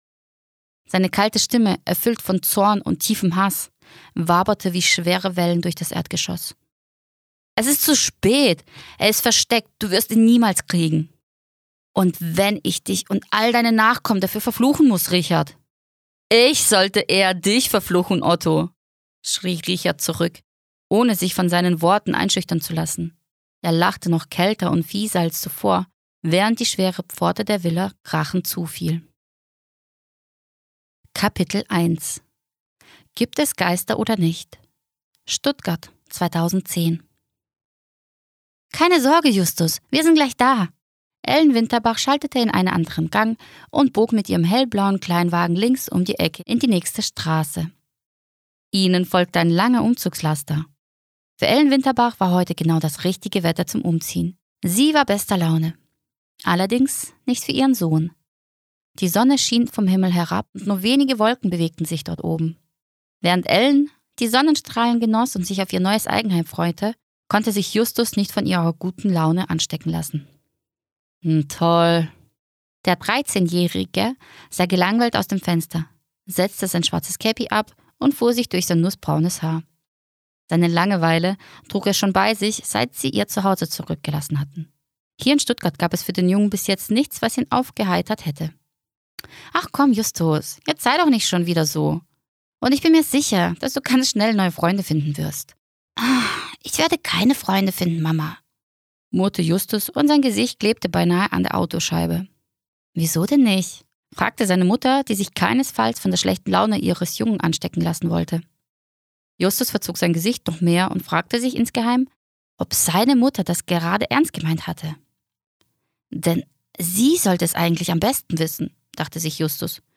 Hoerprobe-Tommi-Felix-ermitteln.mp3